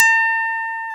Index of /90_sSampleCDs/Roland L-CD701/KEY_E.Pianos/KEY_Rhodes